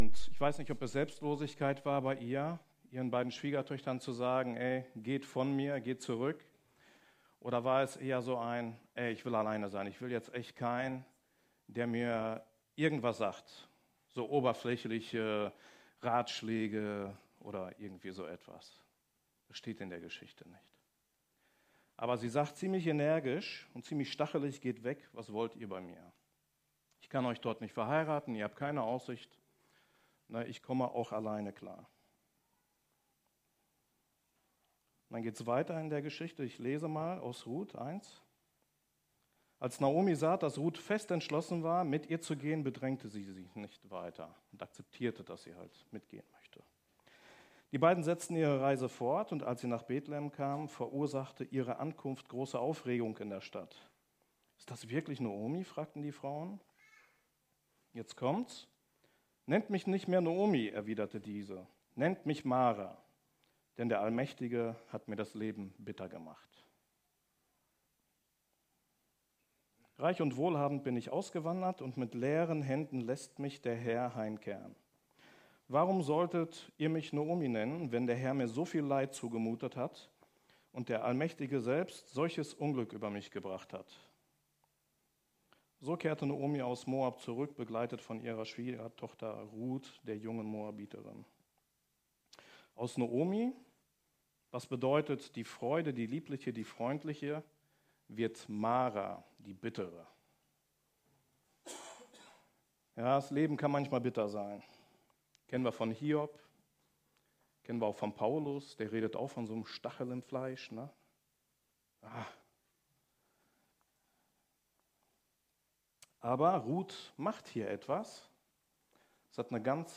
Predigt vom 18. August 2019 – efg Lage